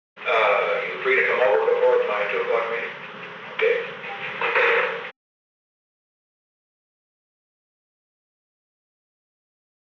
Conversation: 381-016
Recording Device: Old Executive Office Building
On December 18, 1972, President Richard M. Nixon and H. R. ("Bob") Haldeman met in the President's office in the Old Executive Office Building at 1:43 pm. The Old Executive Office Building taping system captured this recording, which is known as Conversation 381-016 of the White House Tapes.